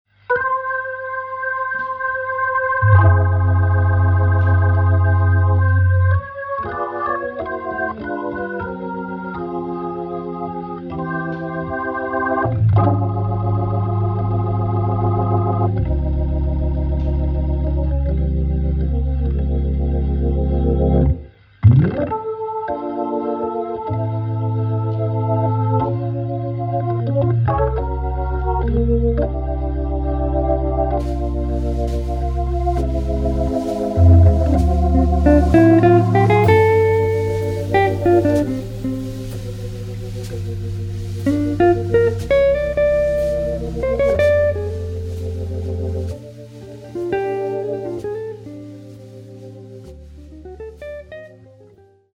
guitar
Hammond organ
drums